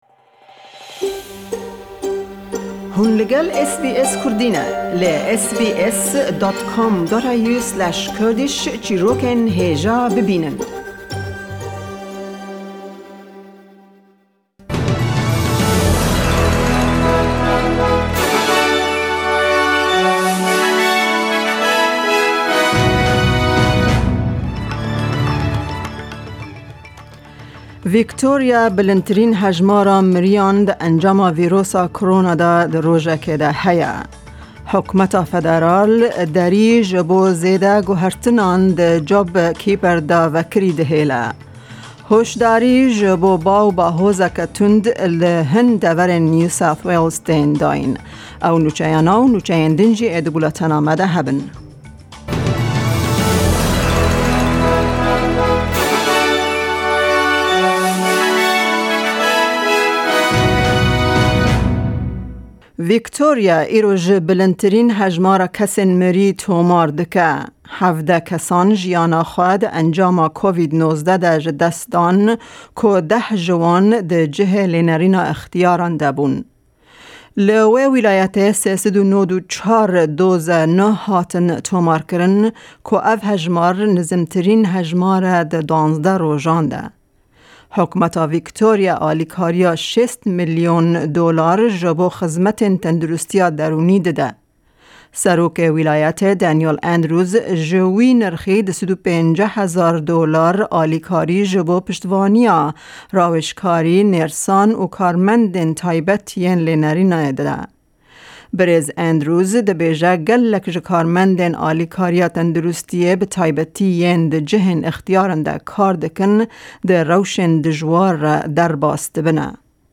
Nûçeyên roja Yekşemê 09/08/2020